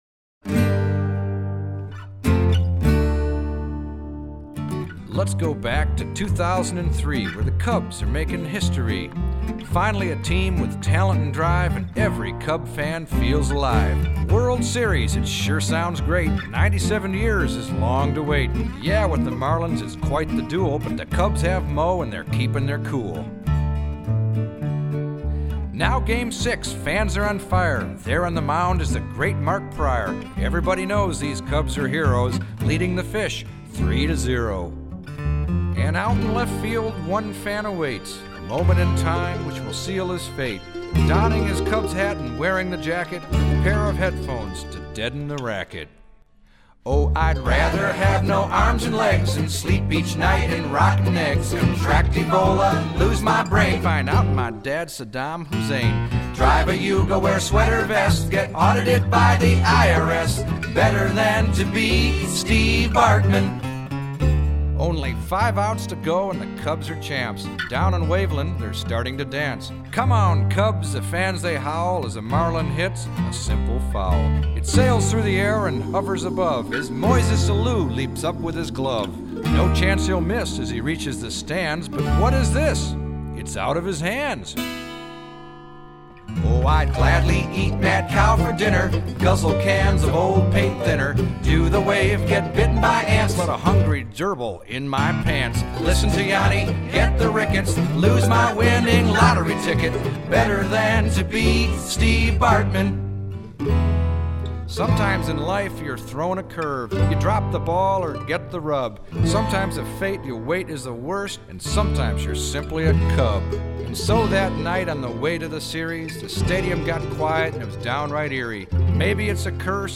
ballad_of_steve_bartman.mp3